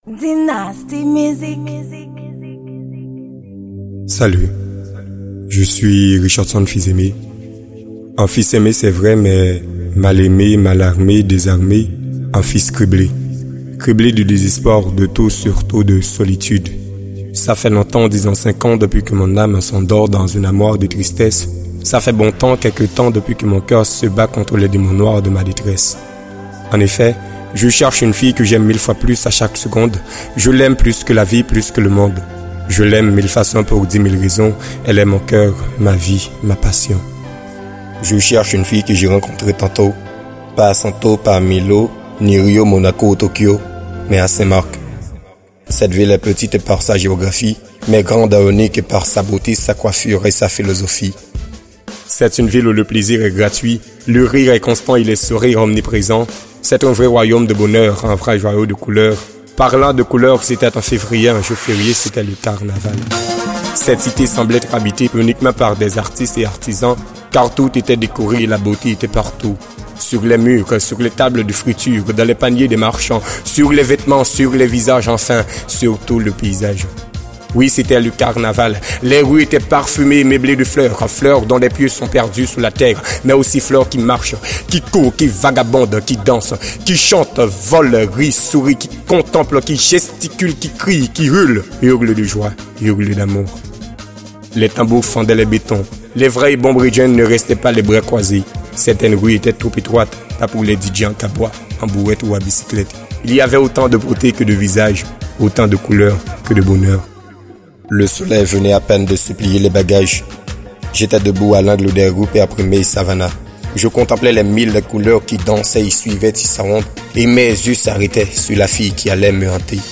Genre: Slam.